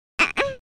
eheh.mp3